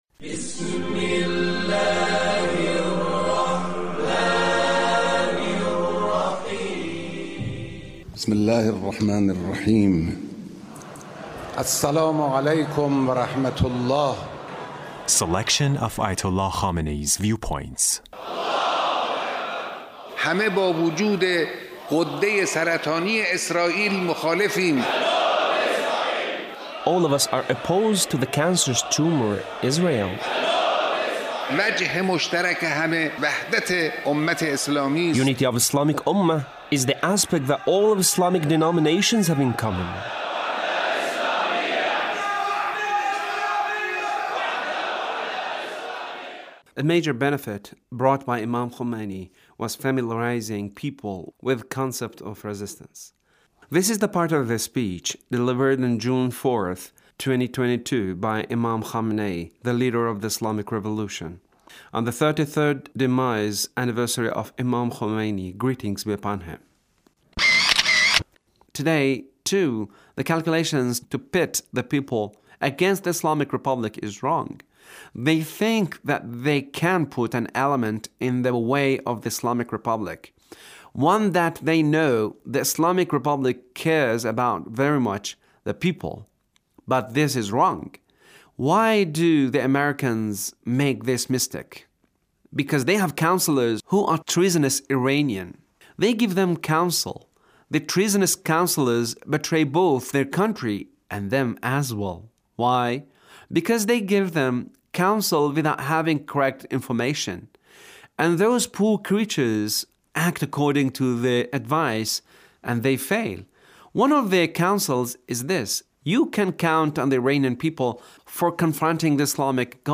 Leader's speech (1447)
The Leader's speech on The Demise Anniversary of The Imam